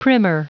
Prononciation du mot primer en anglais (fichier audio)
Prononciation du mot : primer